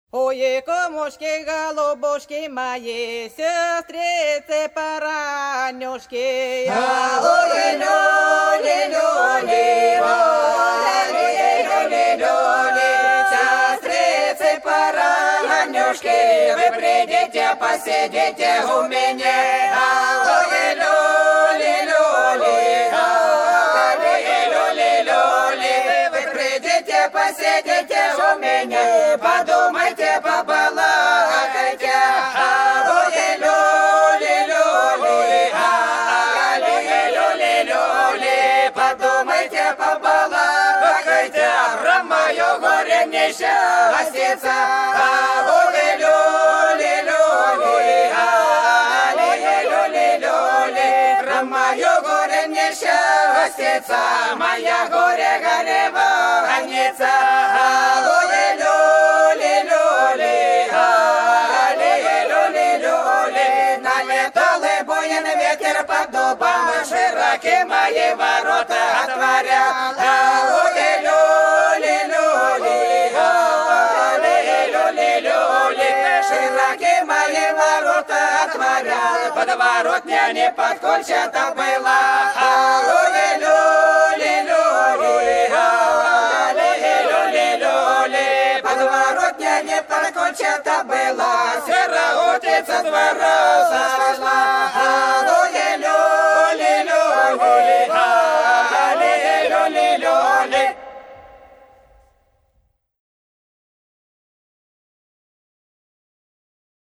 Долина была широкая (Поют народные исполнители села Нижняя Покровка Белгородской области) Кумушки, голубушки - плясовая